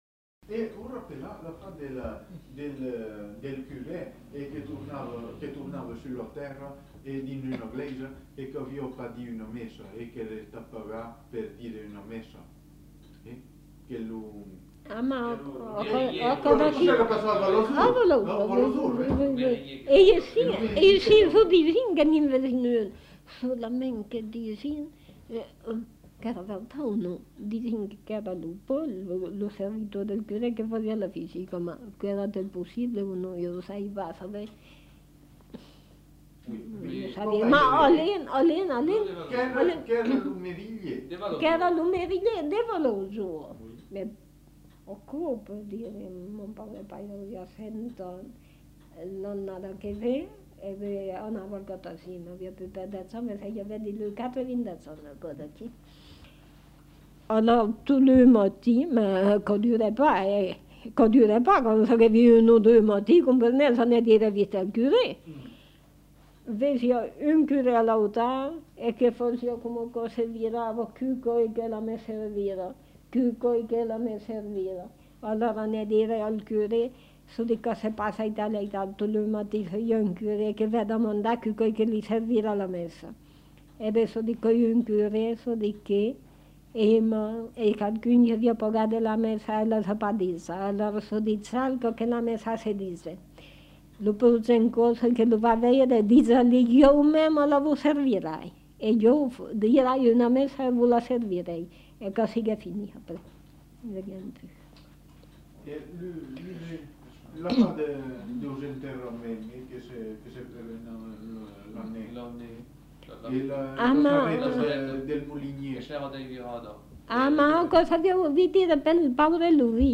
Aire culturelle : Périgord
Genre : conte-légende-récit
Effectif : 1
Type de voix : voix de femme
Production du son : parlé